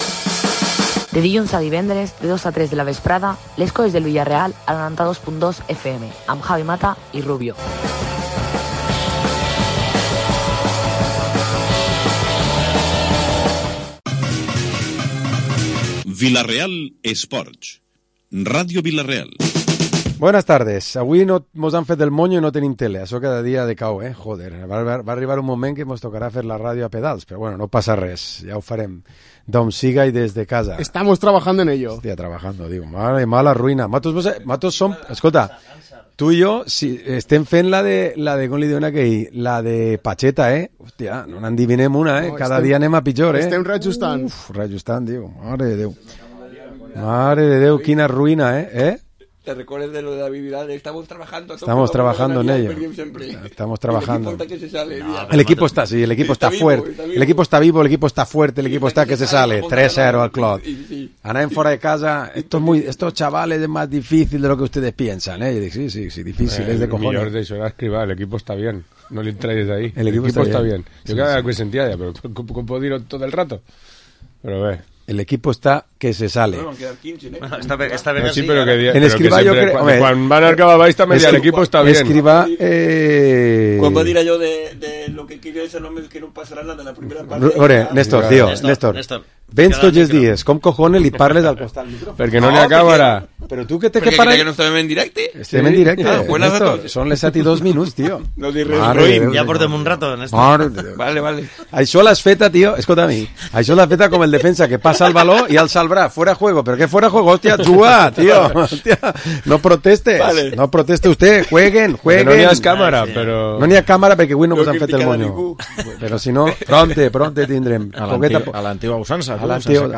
Programa Esports dilluns tertúlia 3 de novembre de 2025